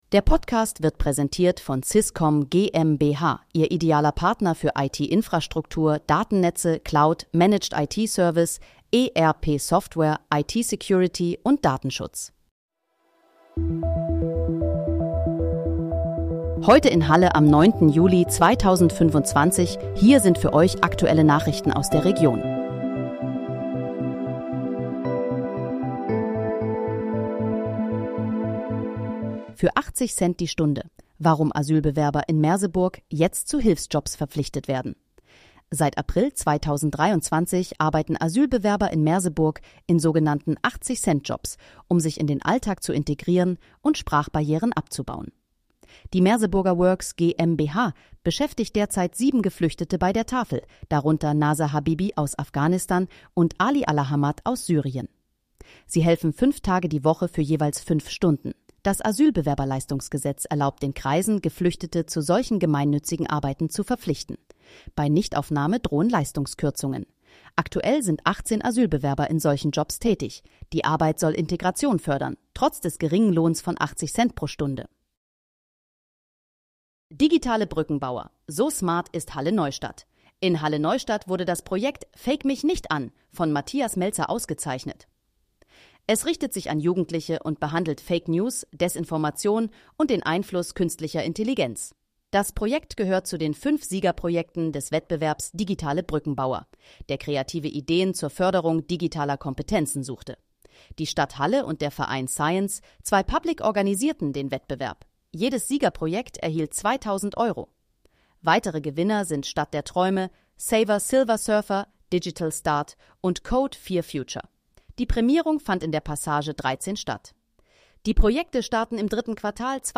Heute in, Halle: Aktuelle Nachrichten vom 09.07.2025, erstellt mit KI-Unterstützung
Nachrichten